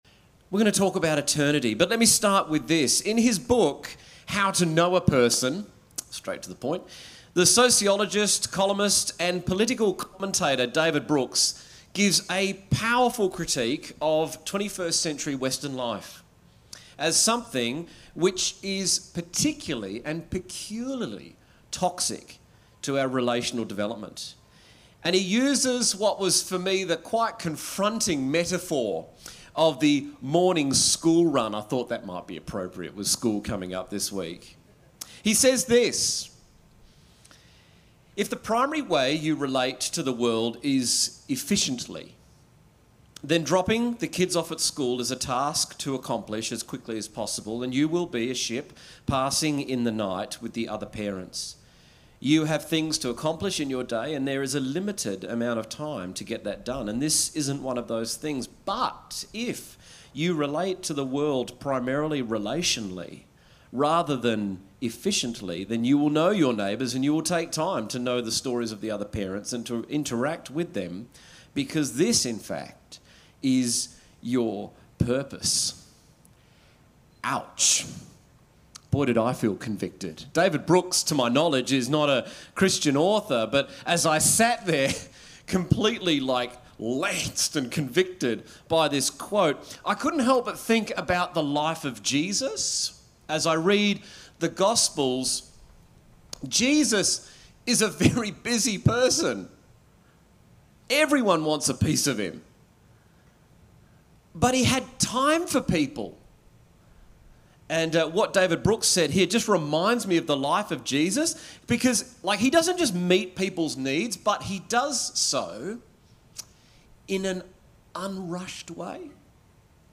A message from the series "Take a Breath."